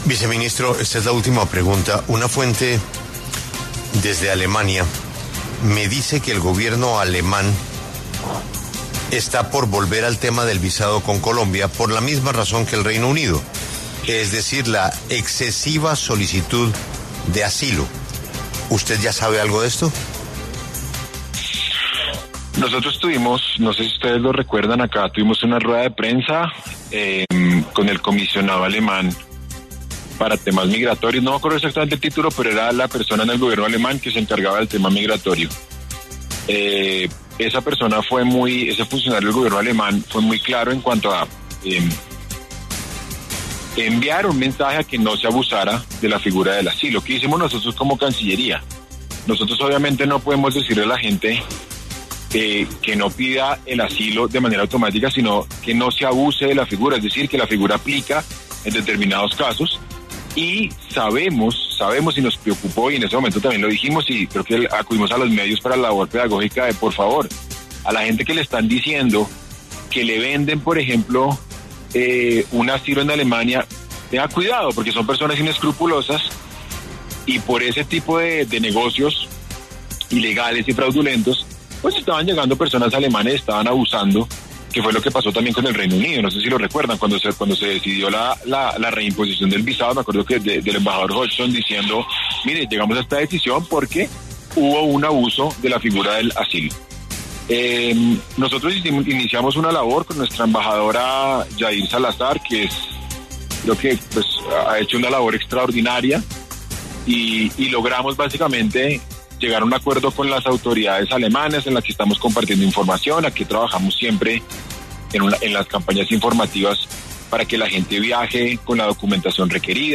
Este lunes, 28 de julio, el director de La W, Julio Sánchez Cristo, reveló en diálogo con el vicecanciller de Colombia, Mauricio Jaramillo, que el Gobierno de Alemania estaría por volver al visado con nuestro país debido al significativo incremento de solicitudes de asilo.